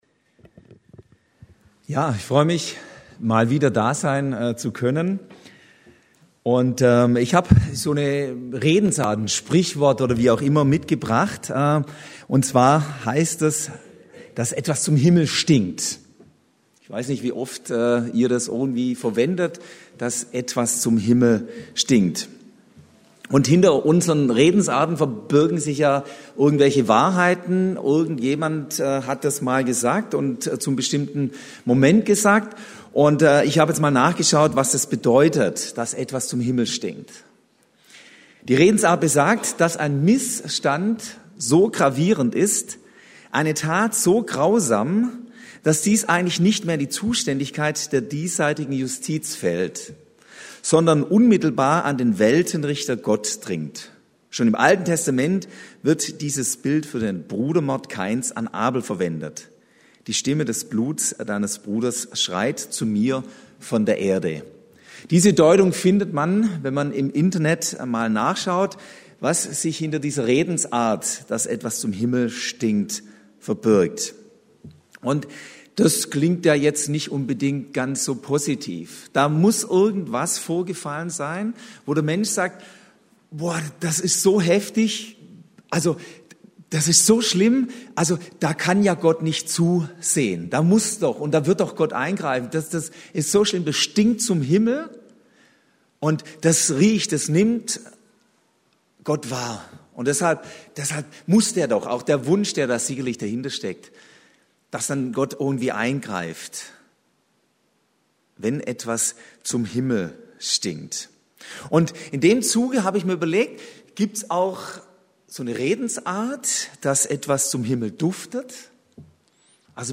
„Etwas stinkt zum Himmel“ – Predigten: Gemeinschaftsgemeinde Untermünkheim